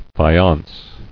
[fa·ience]